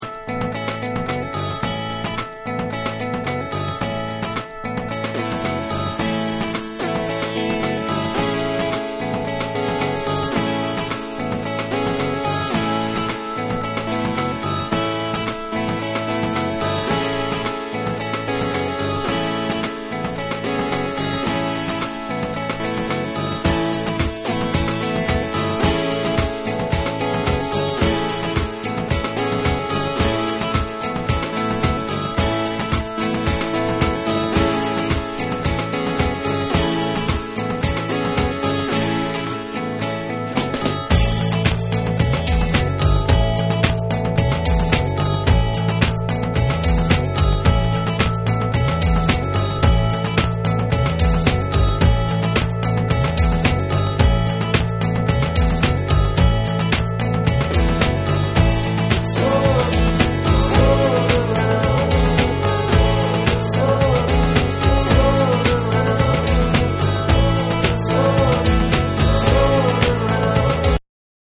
ポップなメロディーラインを基調にオーガニックな雰囲気を醸し出すブレイクビーツが混ざり合うナンバーを中心に収録。
BREAKBEATS